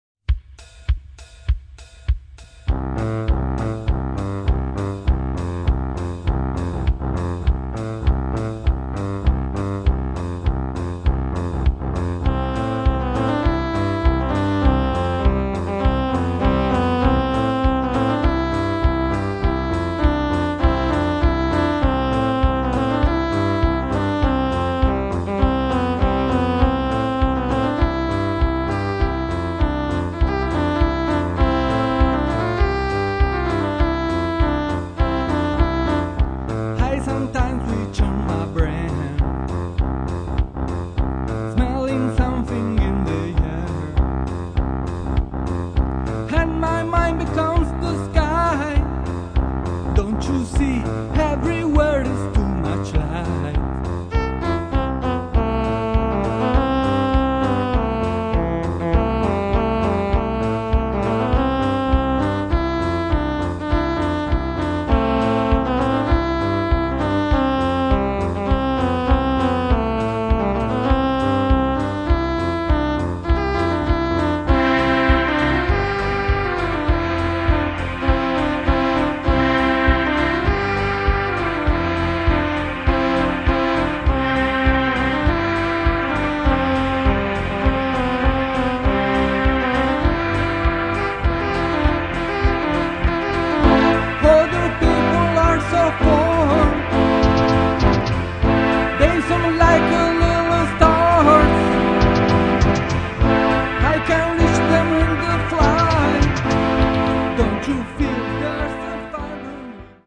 impegnati nel rock alternativo e nel rock dance
alternative rock and rock dance